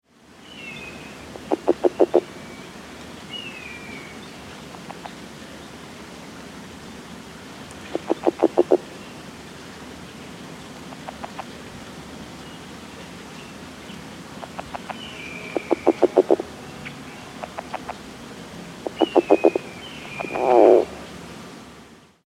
The advertisement call of the California Red-legged Frog can be described as a weak series of 5 - 7 notes, sounding like uh-uh-uh-uh-uh, lasting 1 - 3 seconds that do not have a lot of volume.
The following sounds were all recorded on a sunny afternoon in early March at the edge of a pond in Contra Costa County.
Air temperature was around 60 degrees with a moderate wind.
Sound This is a 22 second recording of the calls of two frogs, including an ending growl. Red-tailed hawks can be heard calling in the background along with other distant singing birds, insects, and wind noise.